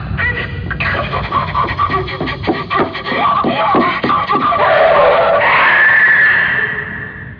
Parker cries out in desperation as the alien prepares to make the final blow.
As Ripley runs through the corridors she hears Lamberts cries.
She hears one last scream then suddenly there's silence.